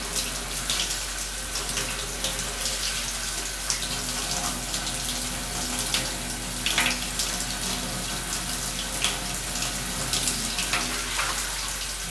water_running_shower_dripping_loop_03.wav